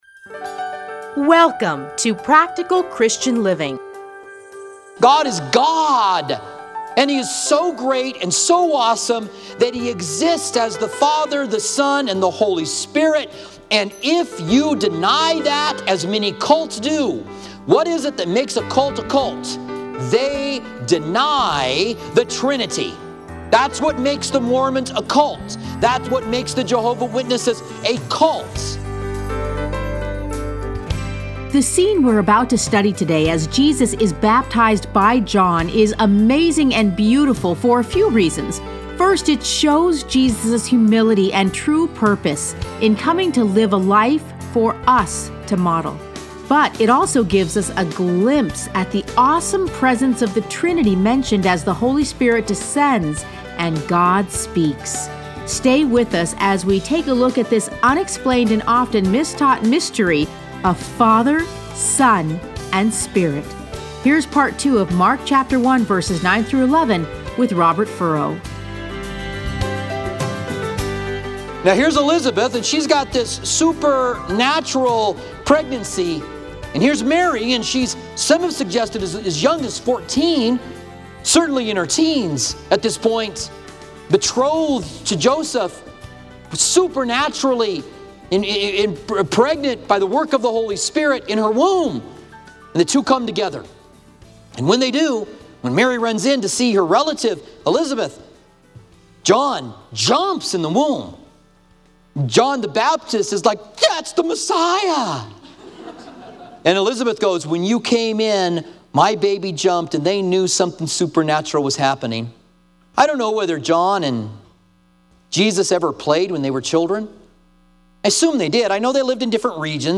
Listen to a teaching from Mark 1:9-11.